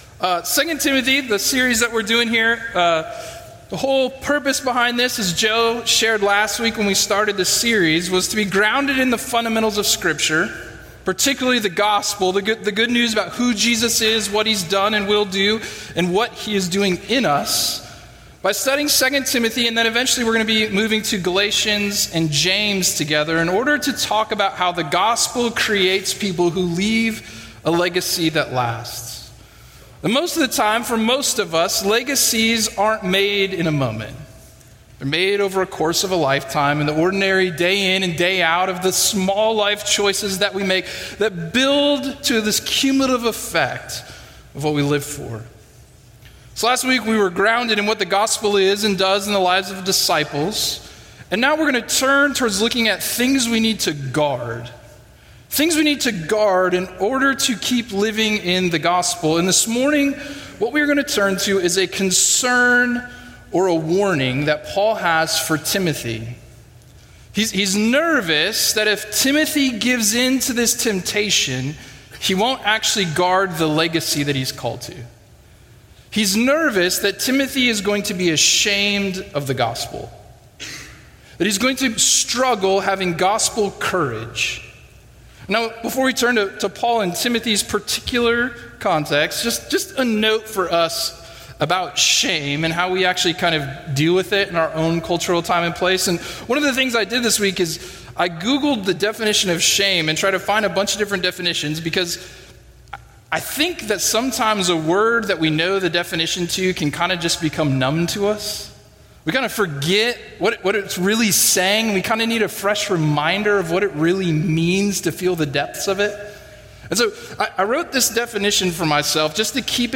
A message from the series "Life Under the Sun."